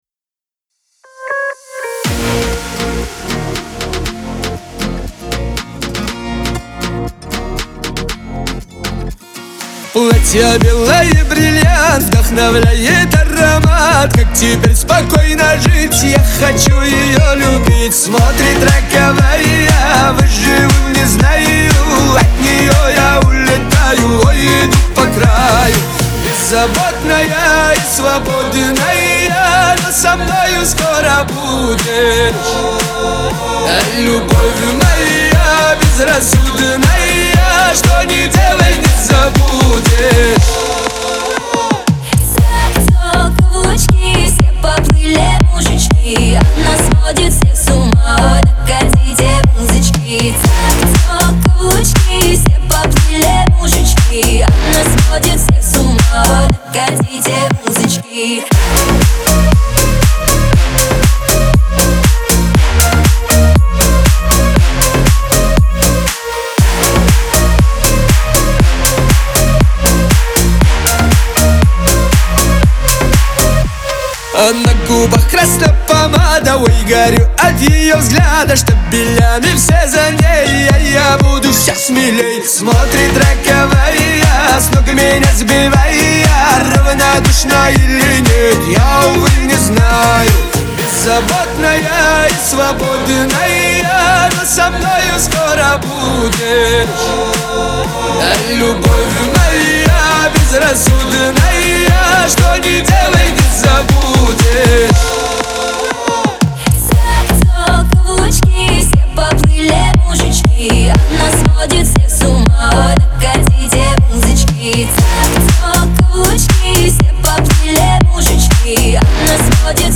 Кавказ поп
эстрада